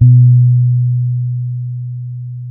-MM DUB  B 3.wav